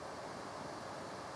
butane.wav